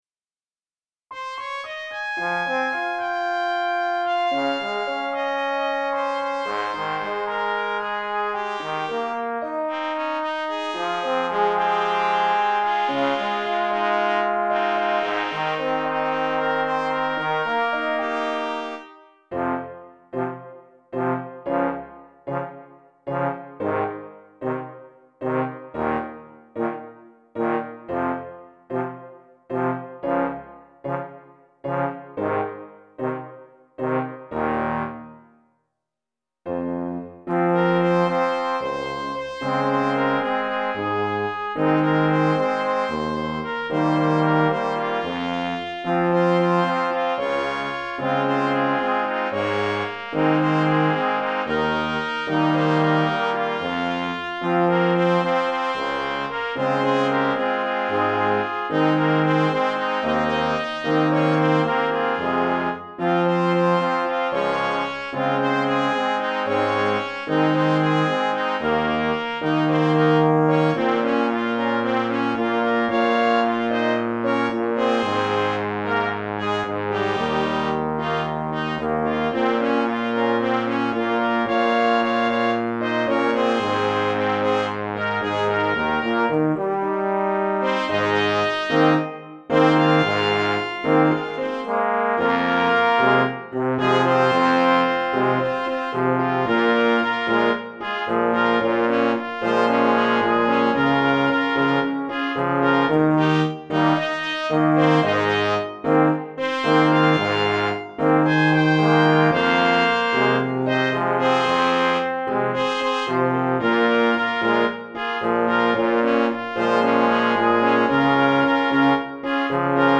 Stimme(B) : Trompete in B, Klarinette 2.